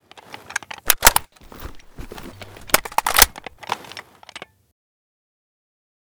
ak74_reload.ogg